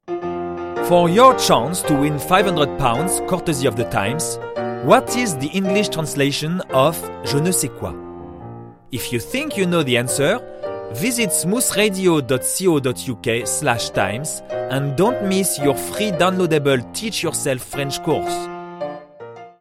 Accent international prononcé/voix medium – Strong French accent/Medium voice